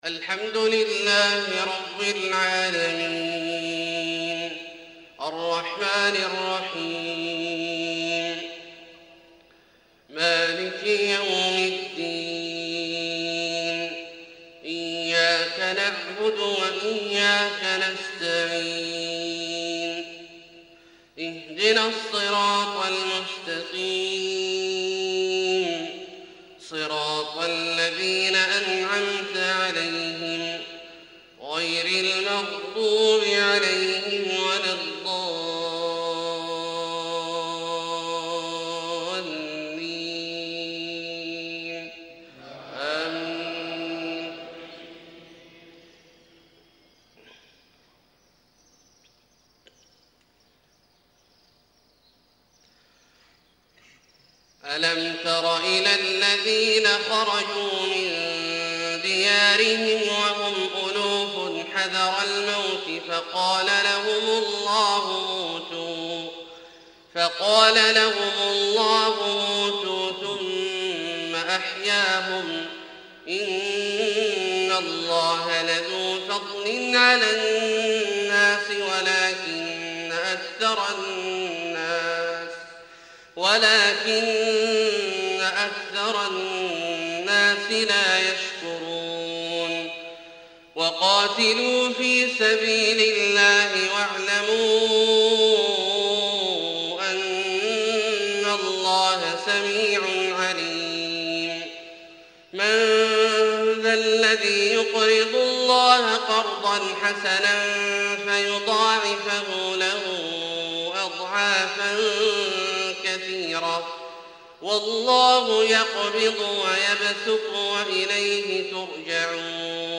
فجر3-4-1432 من سورة البقرة {243-253} > ١٤٣٢ هـ > الفروض - تلاوات عبدالله الجهني